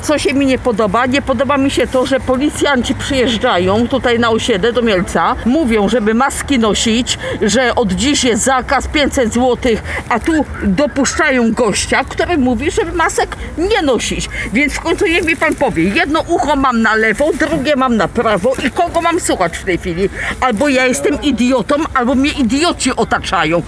Zapytaliśmy mielczan co sądzą o tej argumentacji przedstawianej przez organizatorów spotkania.